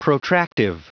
Prononciation du mot protractive en anglais (fichier audio)
Prononciation du mot : protractive